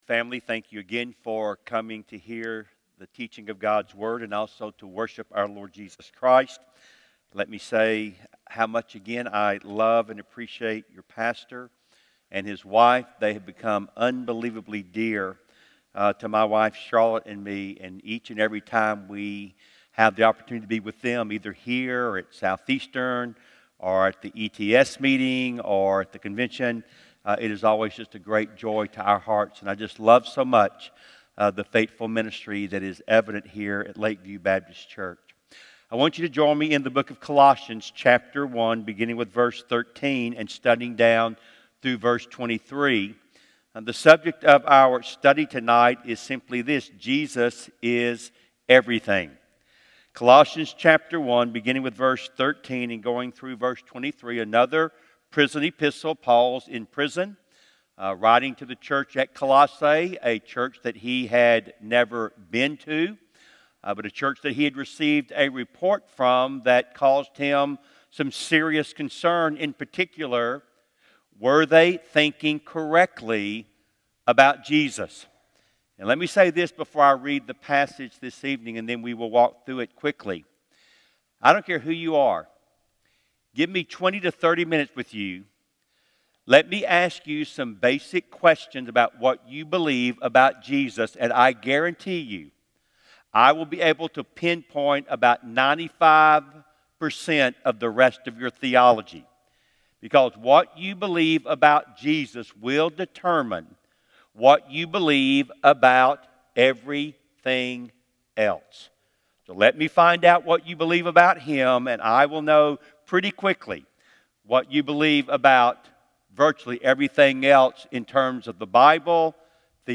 Colossians 1:13-23 Service Type: Sunday Evening 1.